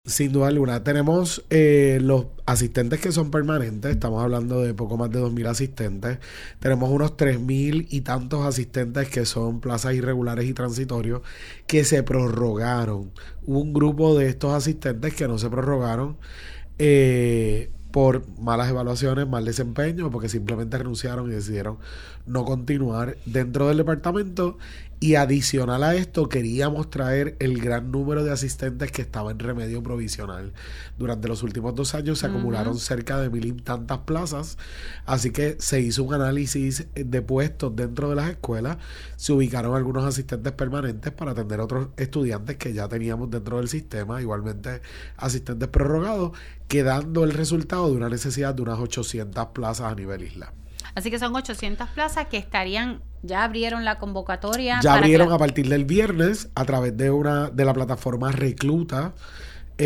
El secretario de Educación, Eliezer Ramos, ofreció en RADIO ISLA 1320 una actualización ante el inicio del año académico 2025- 2026.